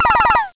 laserShots.wav